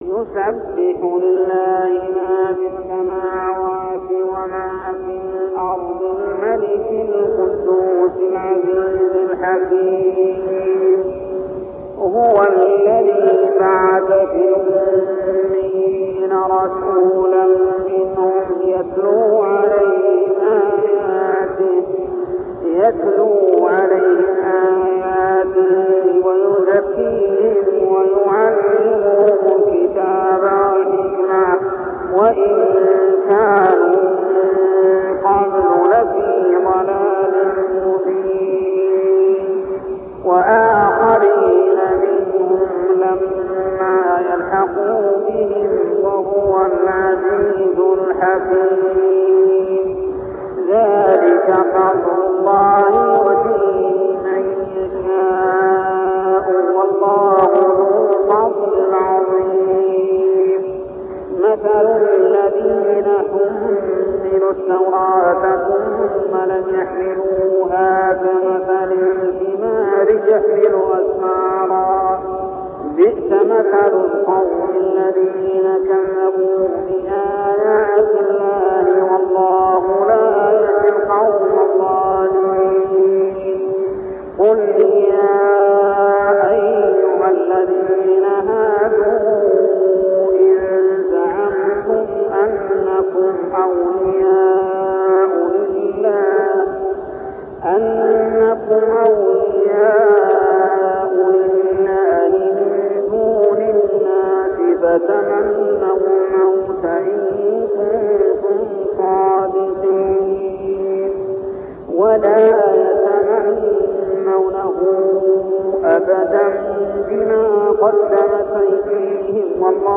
تلاوة من صلاة الفجر سورتي الجمعة و الأعلى كاملة عام 1399هـ | Fajr prayer Surah Al-Jumah and Al-Ala > 1399 🕋 > الفروض - تلاوات الحرمين